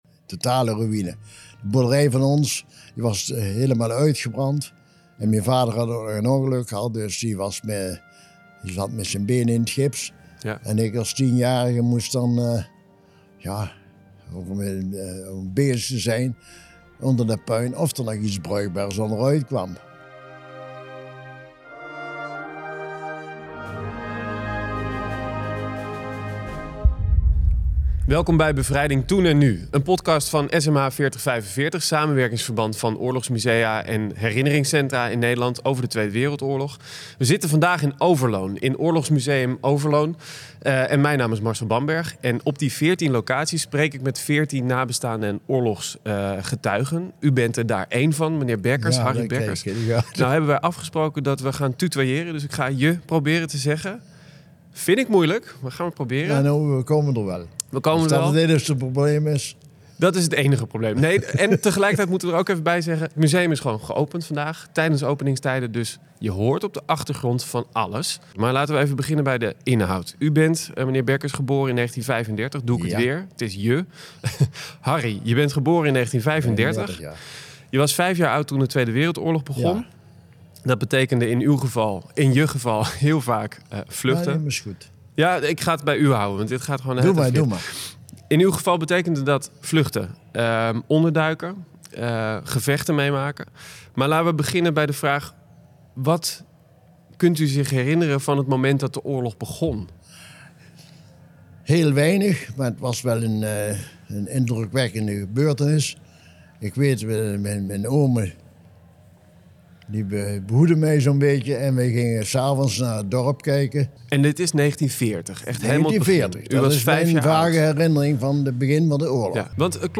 gesprek
opgenomen in het Oorlogsmuseum Overloon.